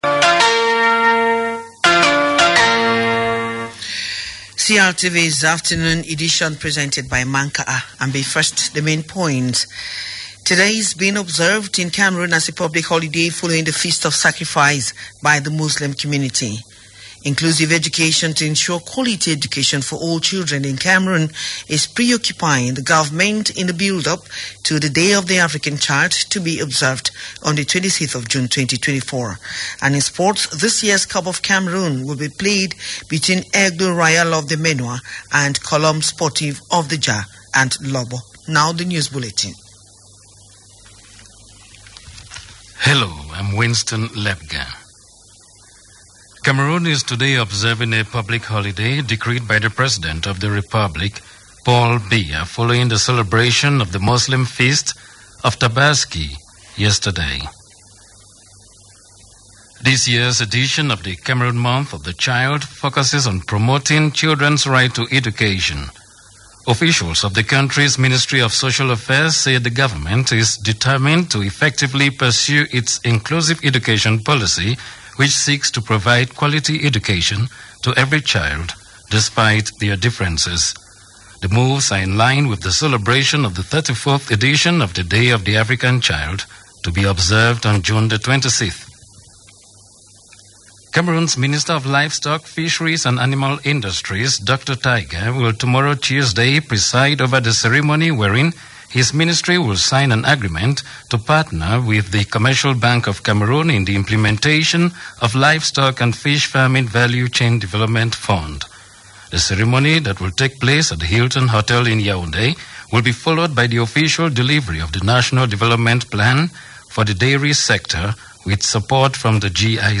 The 3pm News of June 17, 2024 on CRTV - CRTV - Votre portail sur le Cameroun